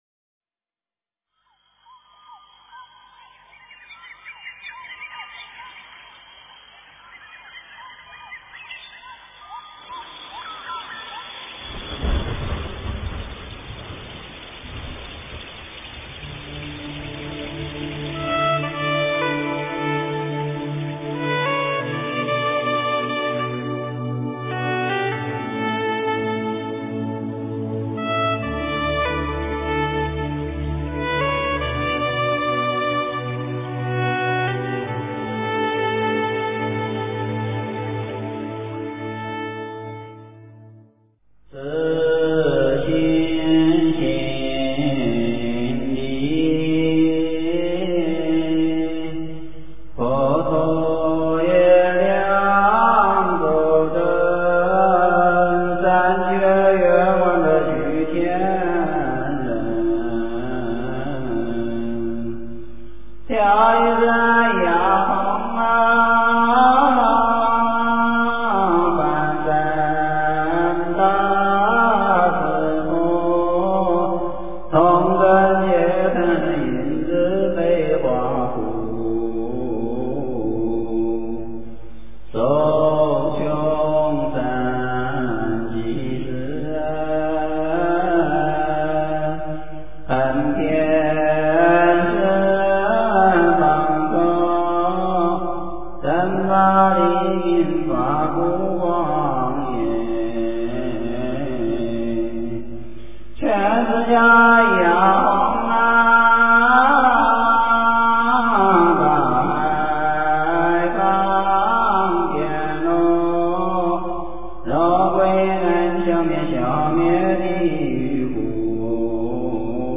佛音 经忏 佛教音乐 返回列表 上一篇： 南无本师释迦牟尼佛--海涛法师率众 下一篇： 五方佛礼赞--佛光山梵呗团 相关文章 八十八佛大忏悔文--如是我闻 八十八佛大忏悔文--如是我闻...